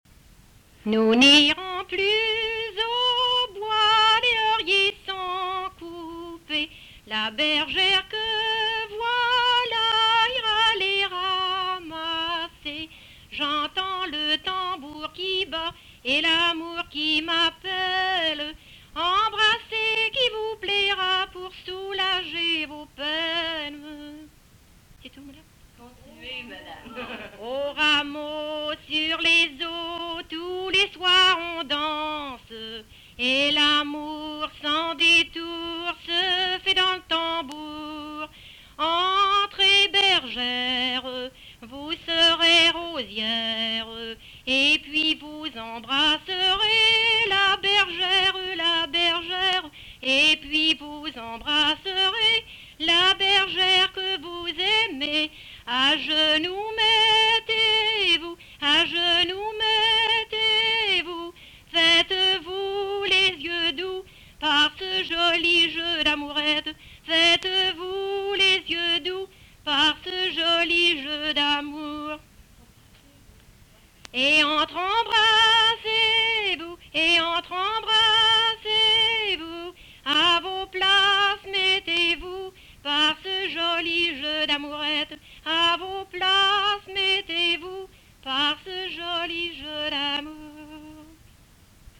Chanson Item Type Metadata
Emplacement Saint-Pierre